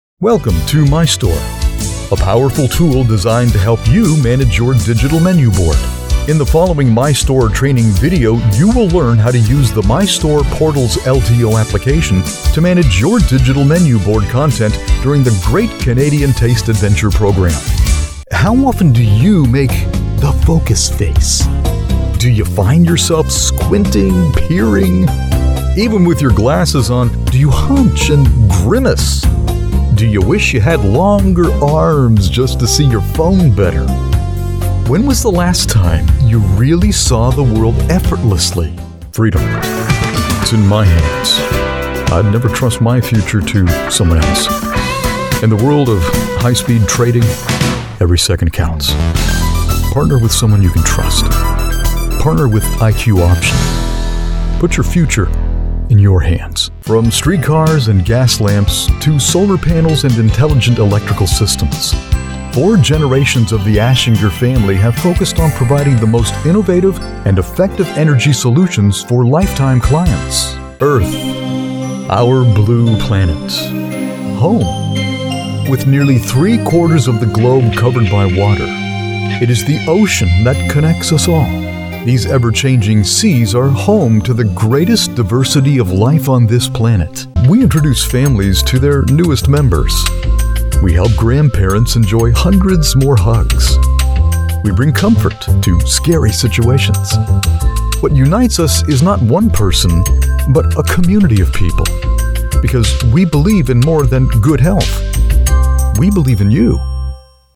Male
Adult (30-50), Older Sound (50+)
Deep, Rich, Smooth, the perfect blend of voice. From Conversational to DJ sound, including, but not limited to, Southern, Pirate and Old Man Voices.
Words that describe my voice are Deep, Natural, Conversational.